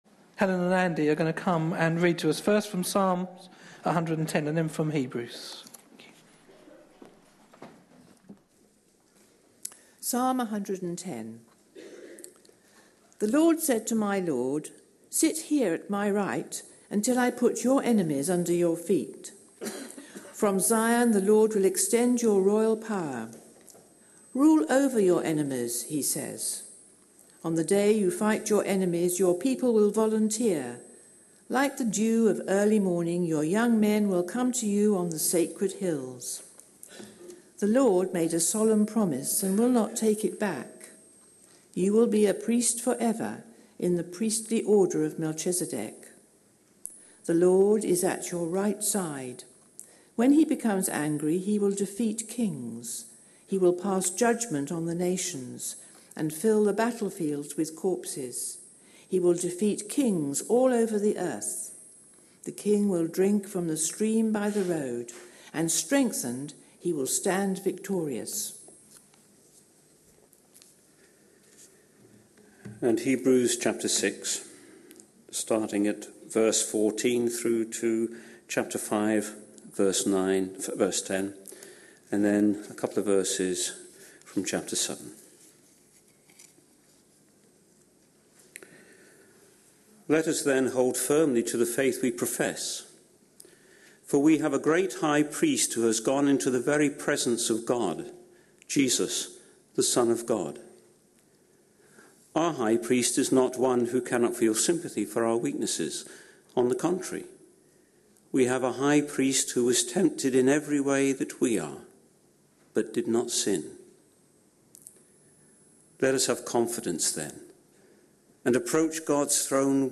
A sermon preached on 14th February, 2016, as part of our Lent 2016. series.